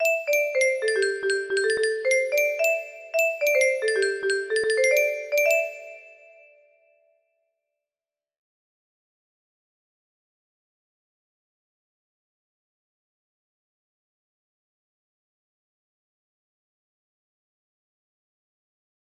Jingle one music box melody